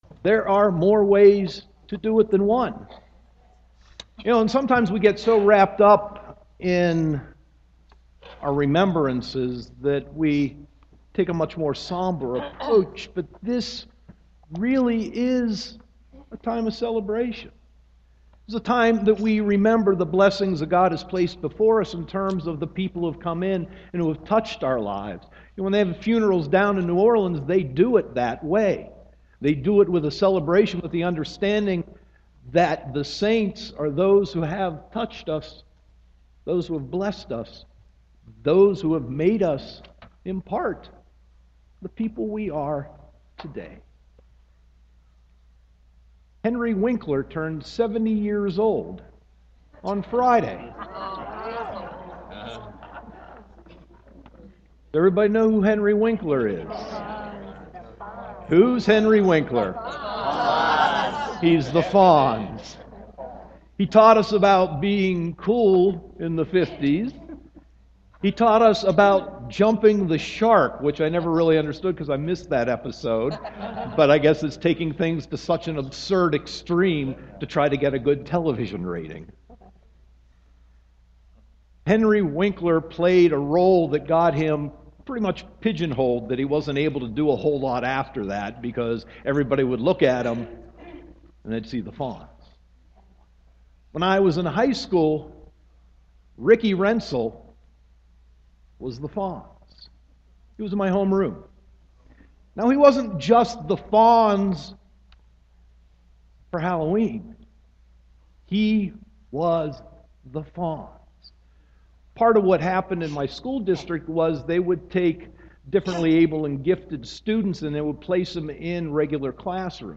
Sermon 11.1.2015